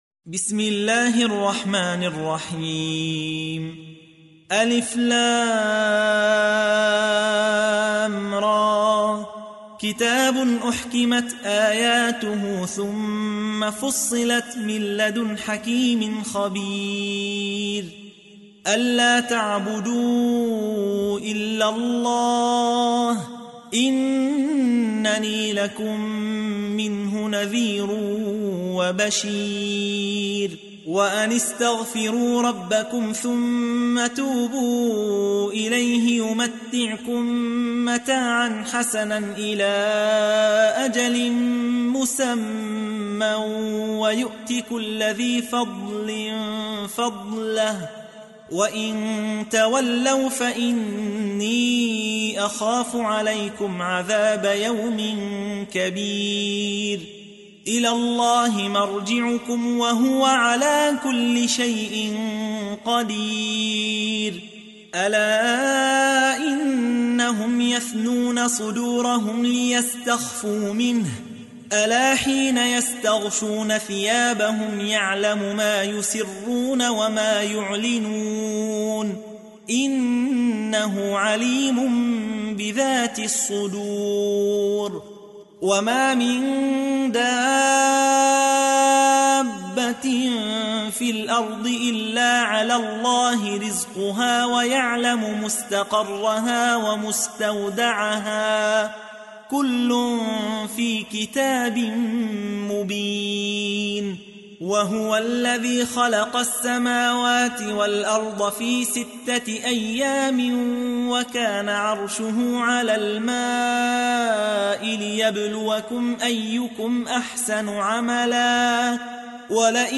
تحميل : 11. سورة هود / القارئ يحيى حوا / القرآن الكريم / موقع يا حسين